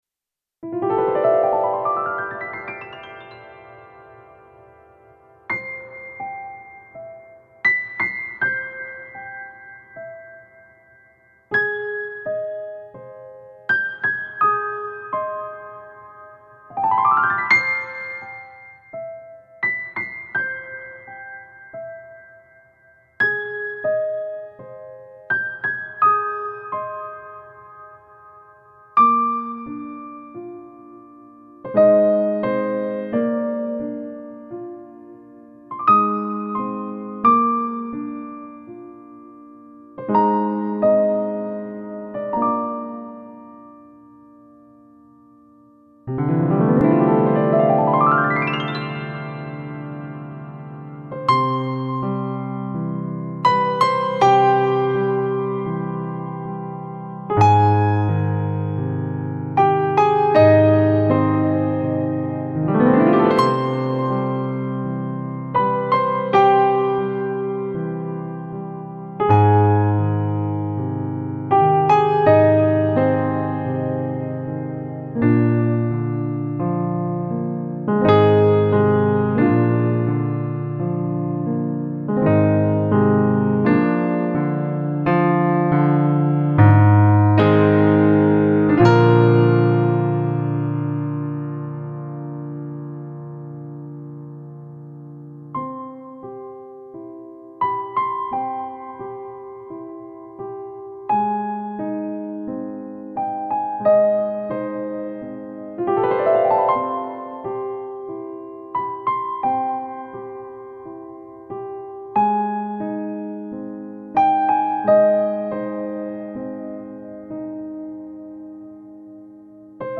主要演奏器乐： 钢琴
乐风：新世纪、疗癒
最平静。抚慰。美丽的纯淨旋律